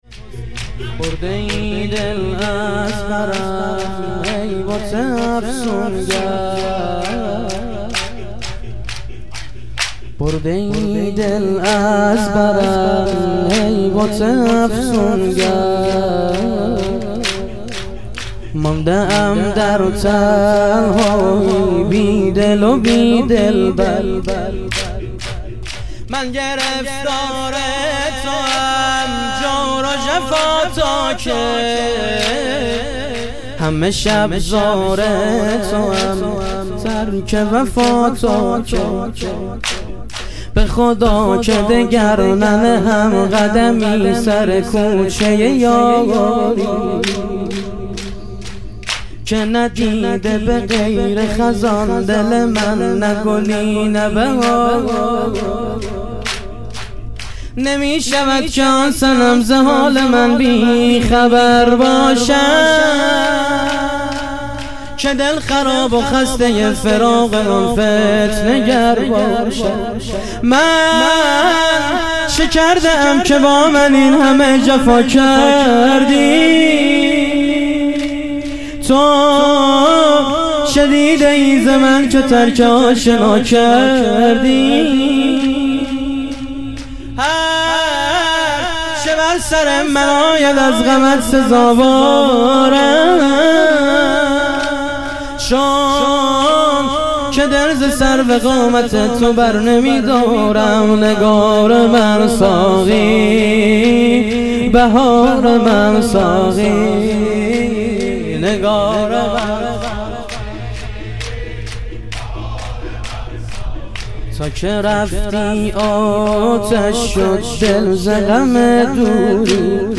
ظهور وجود مقدس امام حسن عسکری علیه السلام - شور